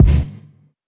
stackrev_kick.wav